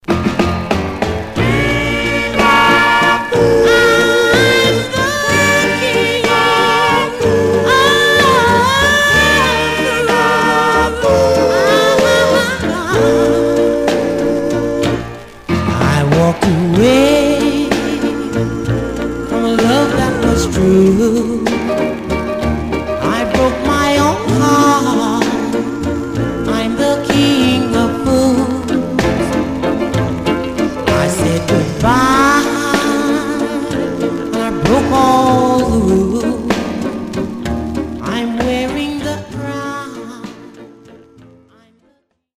Some surface noise/wear Stereo/mono Mono
Male Black Group